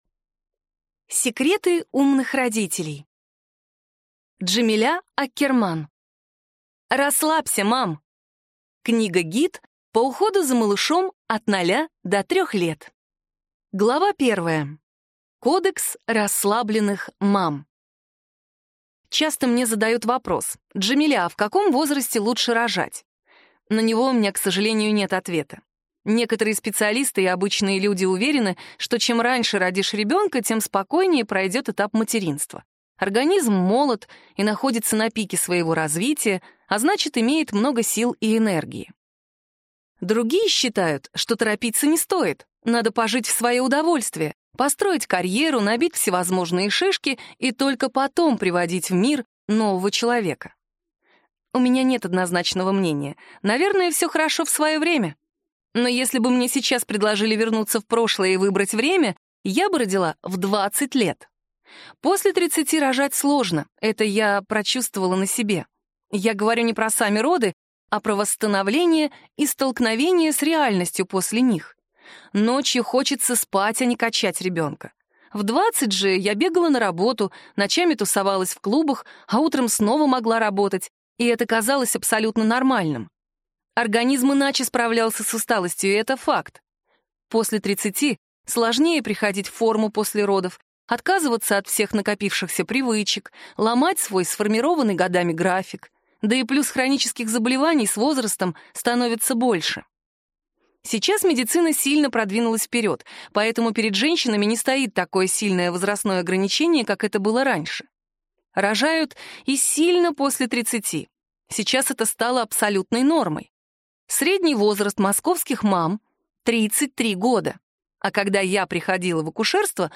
Аудиокнига Расслабься, мам! Книга-гид по уходу за малышом от 0 до 3 лет | Библиотека аудиокниг